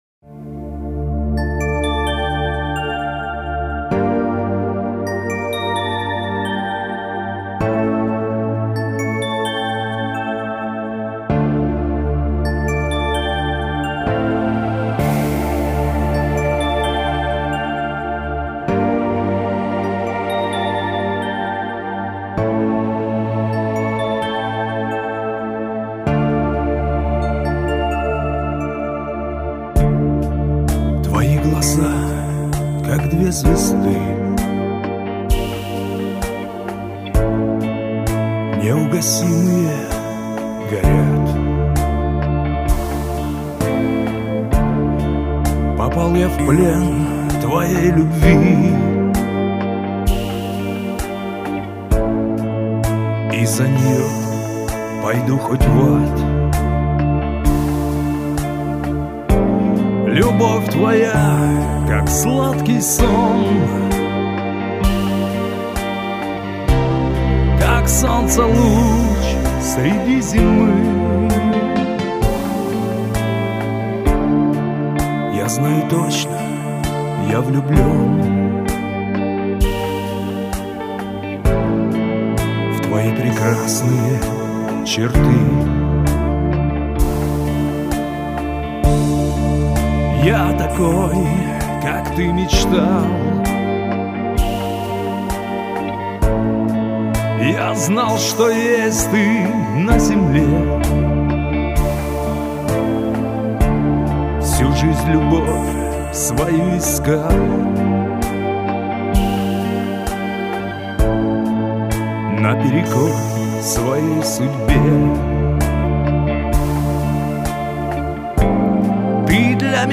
(романс)